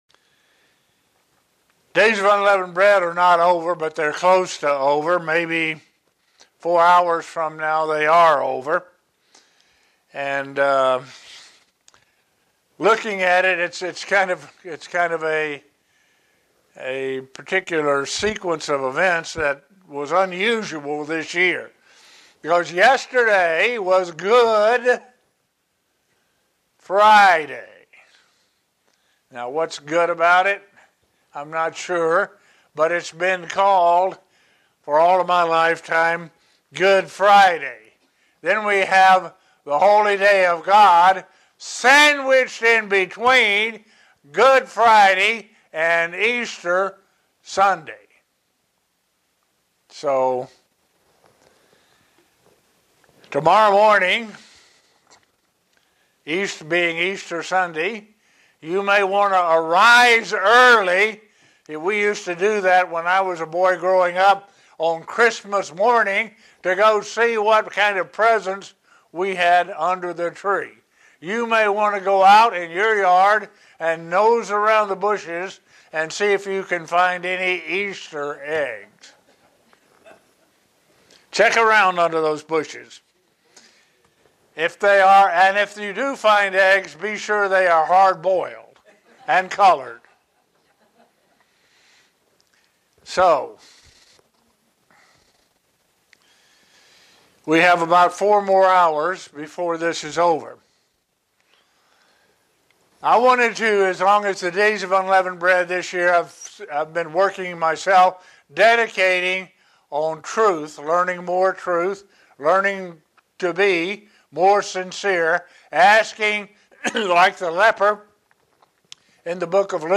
Sermons
Given in Buffalo, NY Elmira, NY